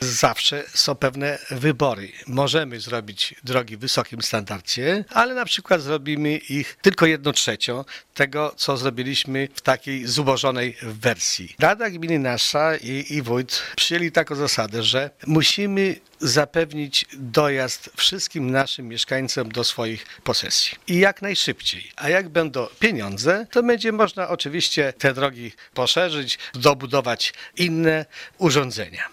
„Inne trasy nie są może tak szerokie, nie ma przy nich chodników, ale dzięki temu samorząd może wybudować ich więcej” - wyjaśnia zastępca wójta Gminy Łuków: